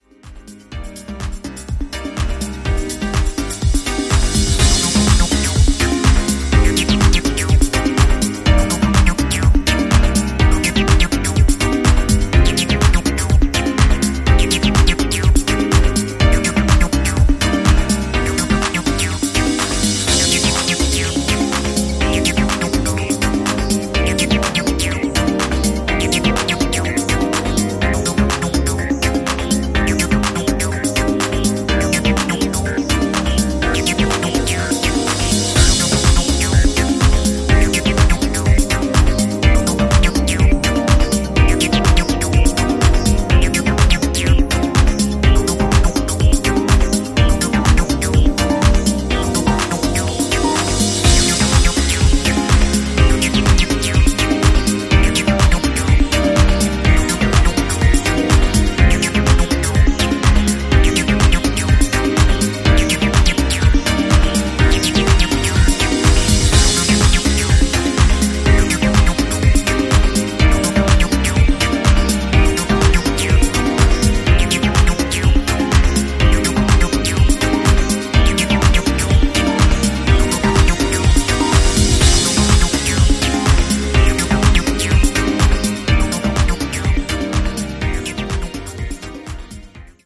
peak-time club material
deep emotional electronica.
Techno